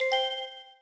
snd_ui_message.wav